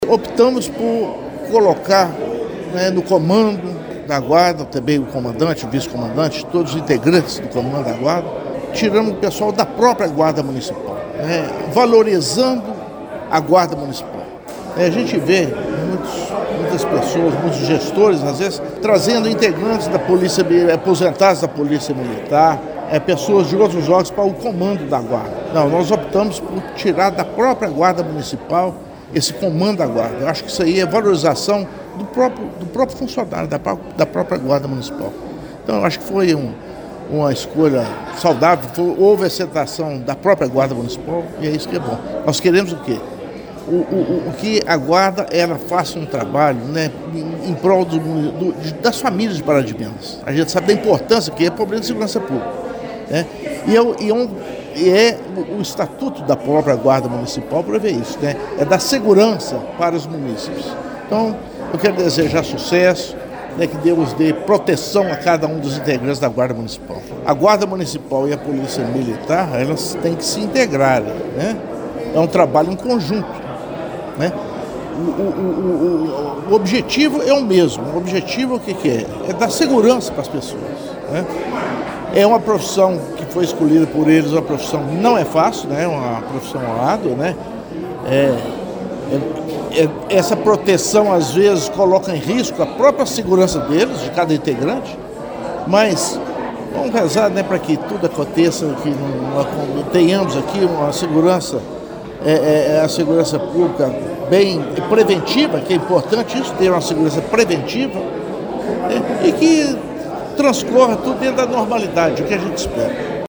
O prefeito Inácio Franco disse que priorizou os agentes para comandar a Guarda Civil Municipal de Pará de Minas, ao contrário de outros gestores. Também defende o bom relacionamento entre as equipes da GCM e da Polícia Militar de Minas Gerais (PMMG) em prol da segurança das famílias paraminenses: